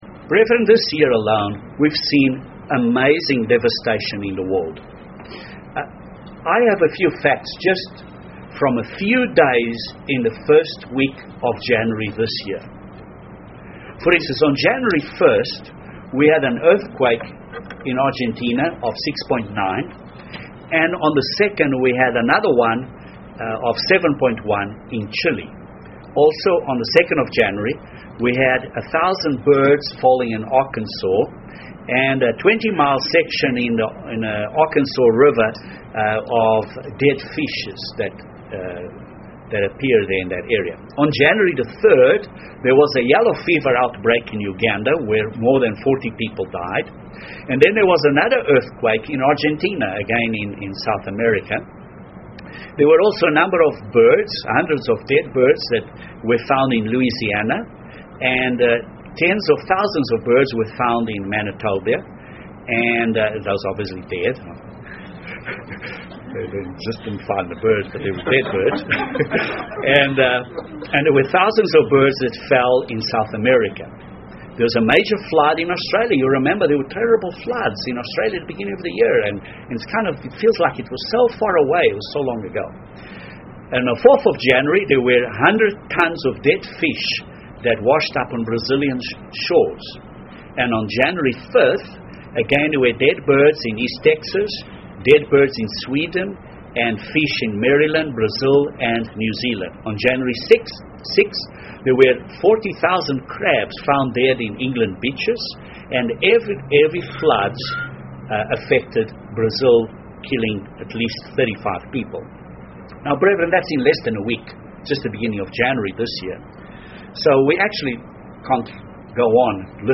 Events in the world are drawing us closer and closer to the day in which God will create a paradise on earth. This sermon reviews scriptures which demonstrate how God will change the world from its current devastation to a wonderful world tomorrow.